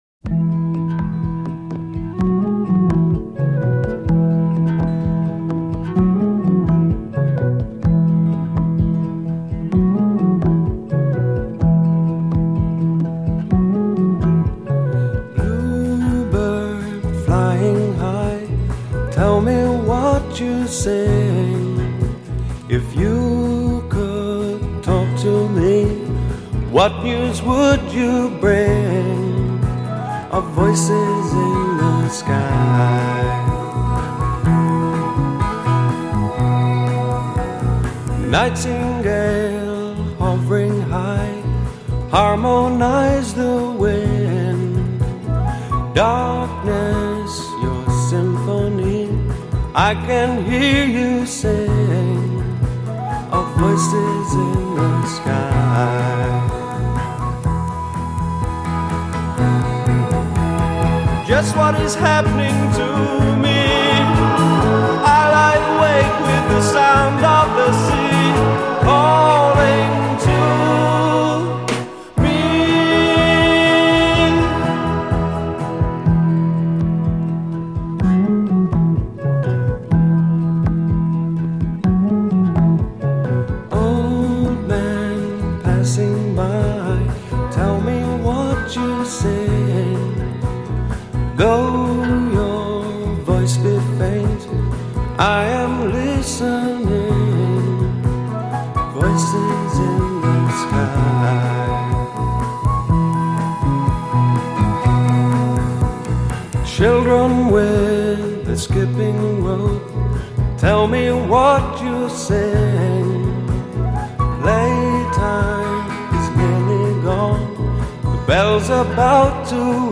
pastoral, flute-and-mellotron infused progressive rock